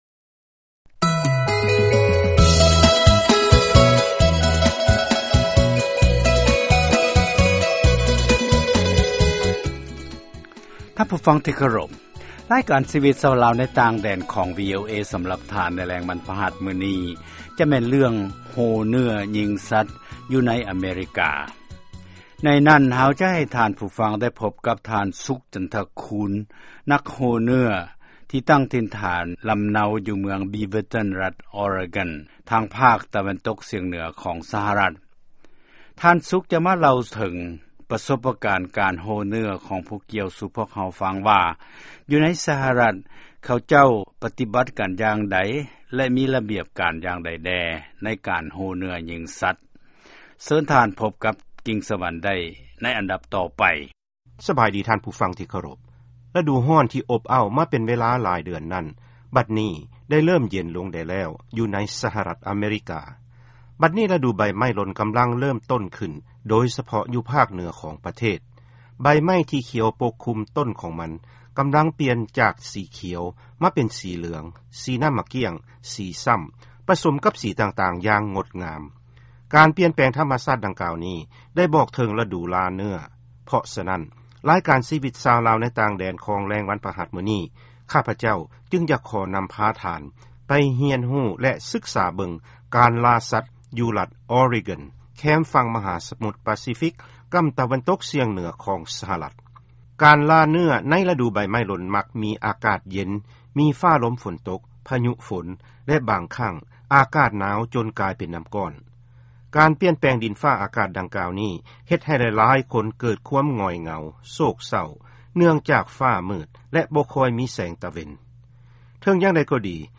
ຟັງການສໍາພາດກ່ຽວກັບ ການລ່າເນື້ອໃນ ສະຫະລັດ